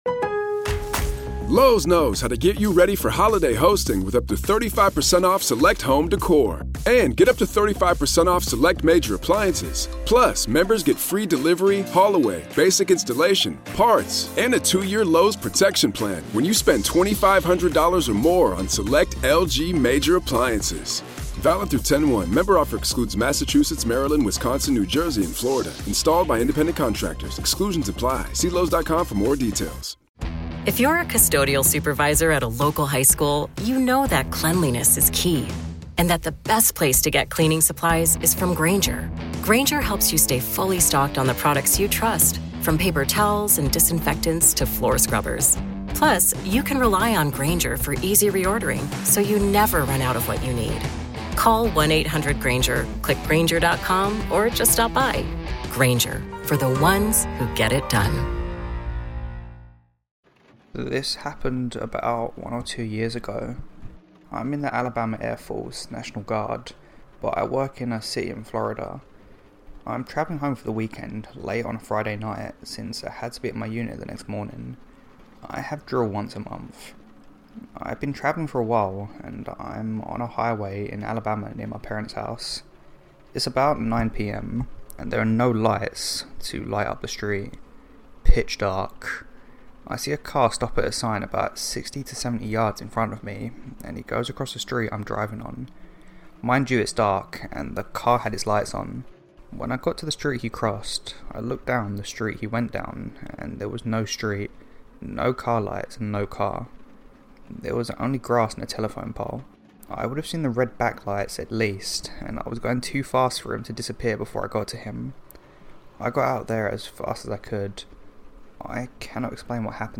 Stories narrated with permission: